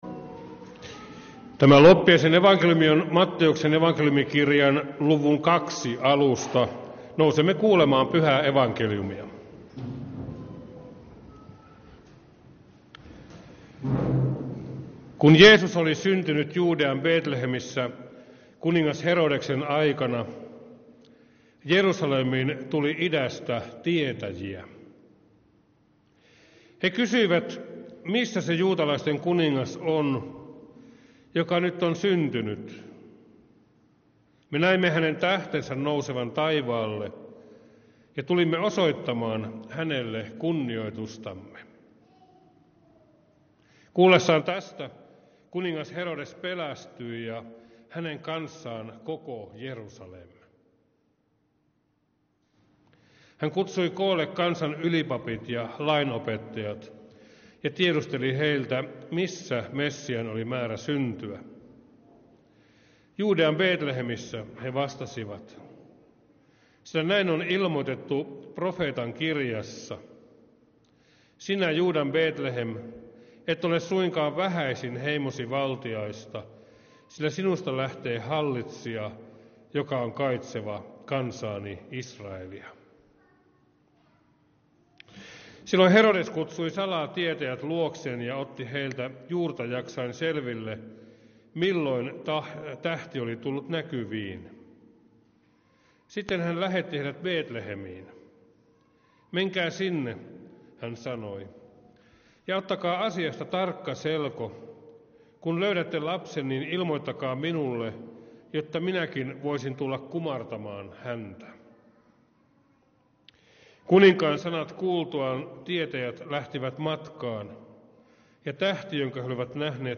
Kokoelmat: Lahden lutherin kirkon saarnat